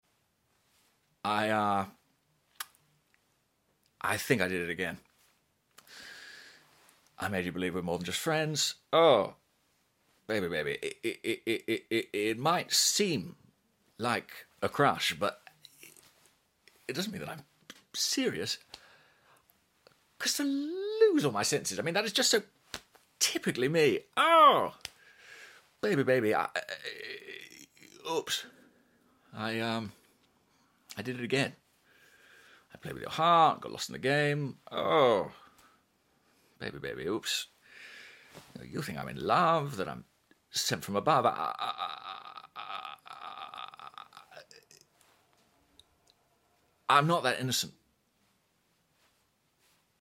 🎭 Dramatic Monologue: Oops! … Sound Effects Free Download